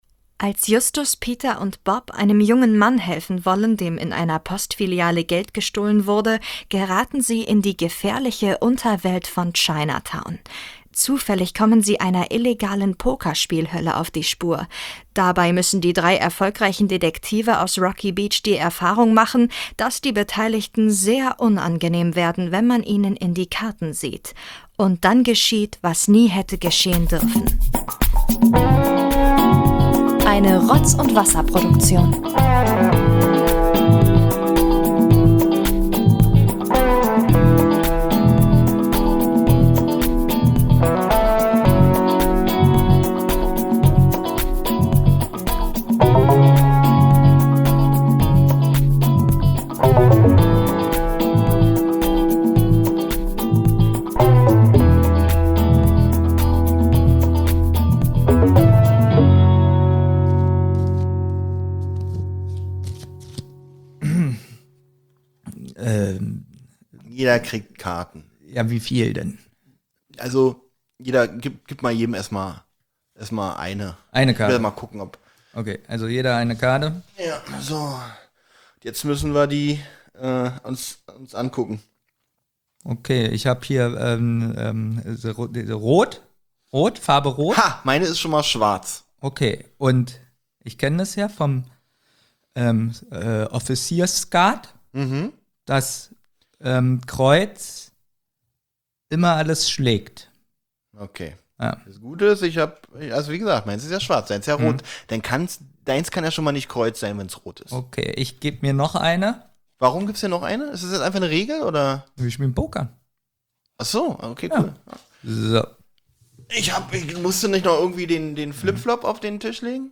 Es gibt nicht nur Statistiken und nackte Zahlen aus 50 Folgen Zentrale, es wird auch geschmatzt, gekaut, übersteuert, gekalauert und ziemlich niveaulos an manchen Stellen.